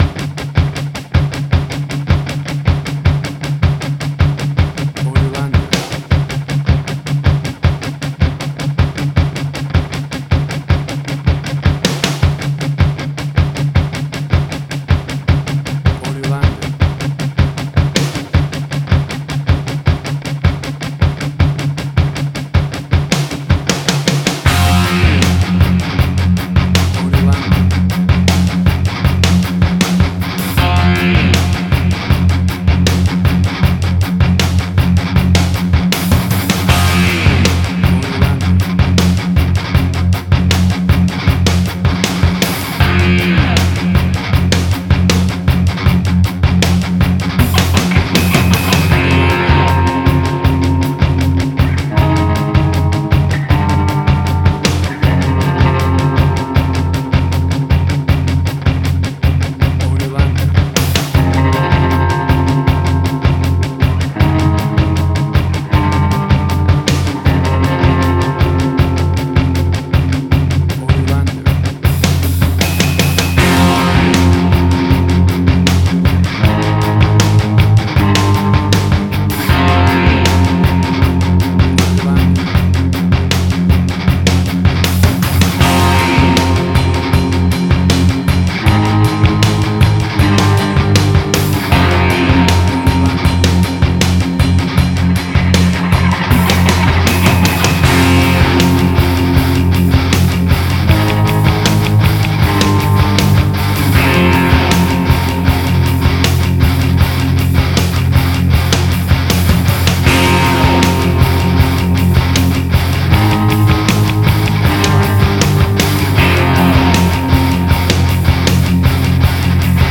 Hard Rock
Heavy Metal.
Tempo (BPM): 79